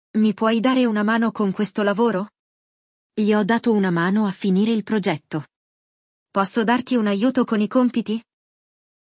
No episódio de hoje, você poderá acompanhar um diálogo realista e imergir no idioma de forma intuitiva. O segredo é ouvir atentamente, repetir as frases e prestar atenção na entonação dos falantes nativos.